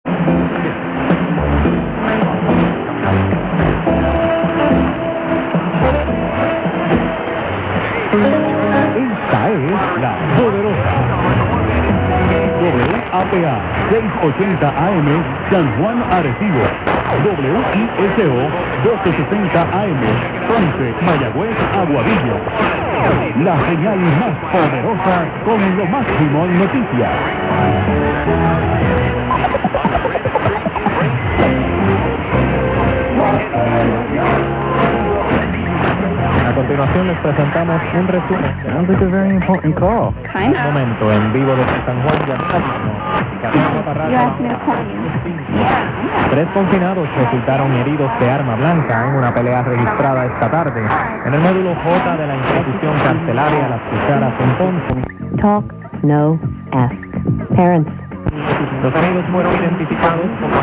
Classic phasing demonstration with local WRKO-680 Boston (tx Burlington, MA) in null, allowing reception of WAPA San Juan, Puerto Rico with "Poderosa" slogan and "WAPA" ID.  Null depth illustrated by taking phaser out of null twice, showing WRKO with talkshow.
5 AUG 2004 at 0005 UTC from Granite Pier - Rockport, MA, USA
WRKO is 50 kW at 33 miles / 53 km.  Distance to WAPA approximately 1700 miles / 2735 km.